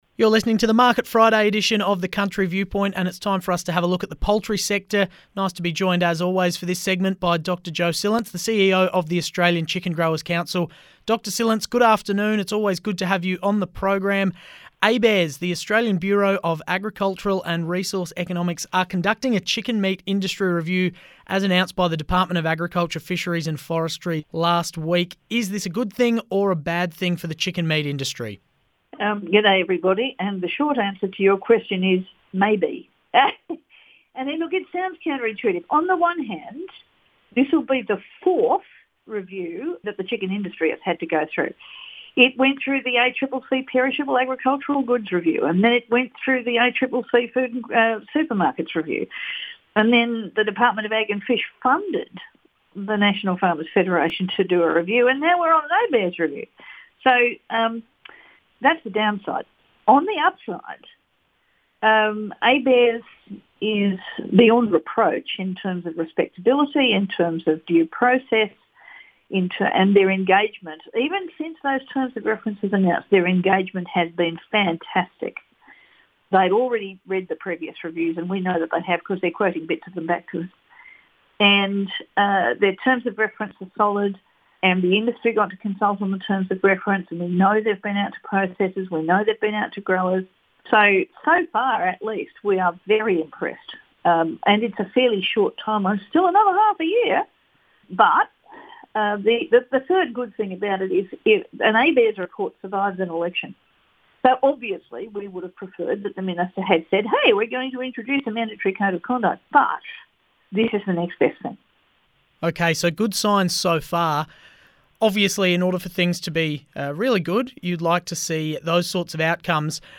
Radio Interviews on Flow FM, South Australia